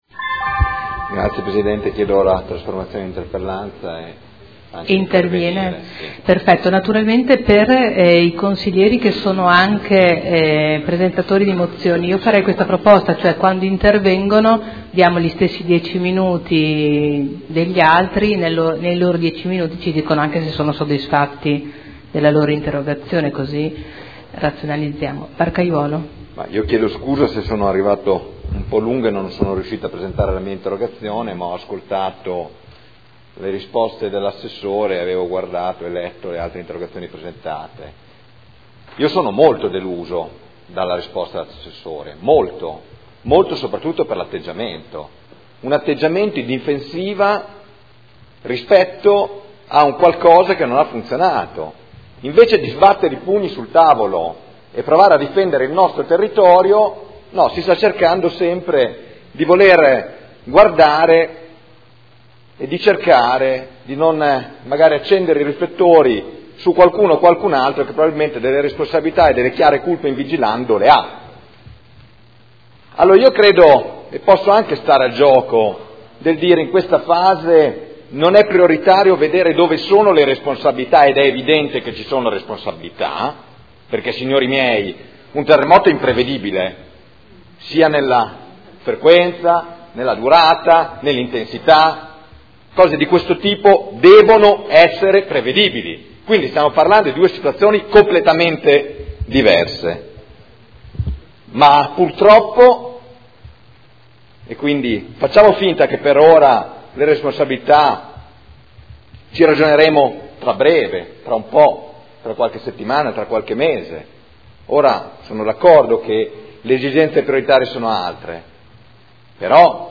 Seduta del 30/01/2014. Chiede trasformazione in interpellanza e apertura dibattito su interrogazioni riguardanti l'esondazione del fiume Secchia.